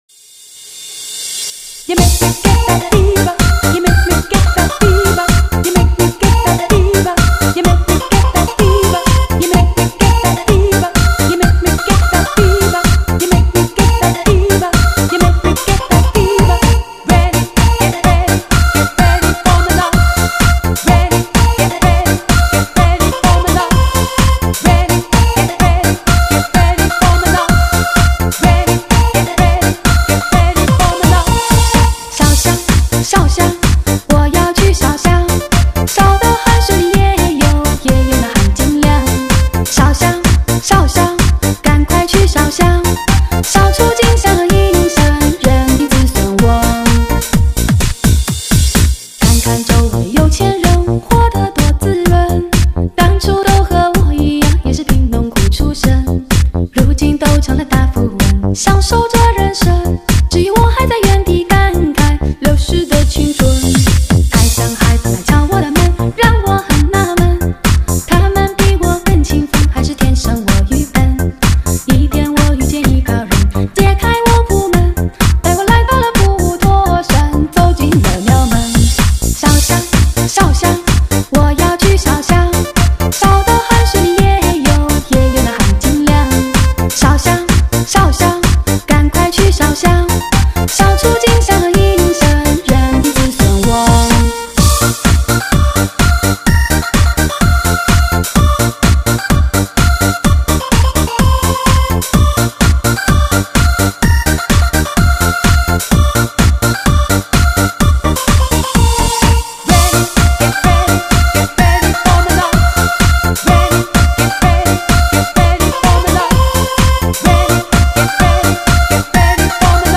最热中文慢摇流行舞曲，触动你听觉的深邃渴望，在律动的节拍中寻找新的撞击与灵感，全力散射非你